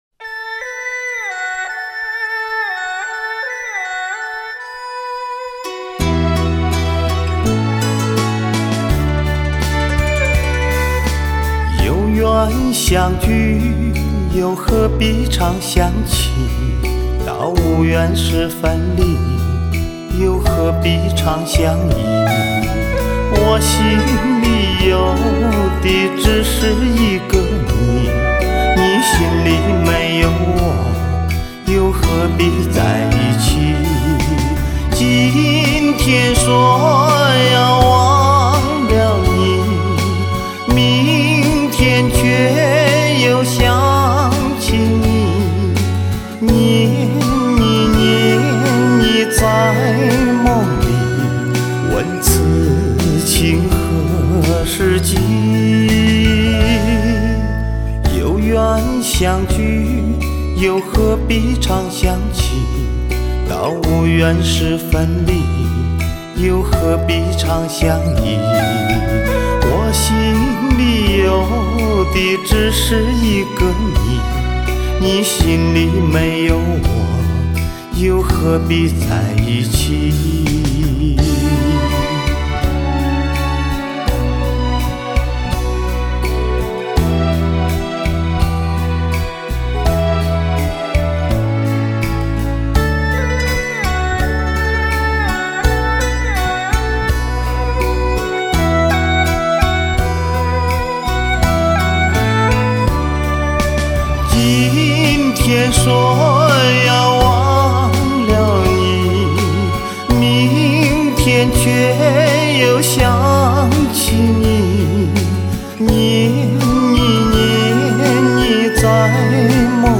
男中音款款深情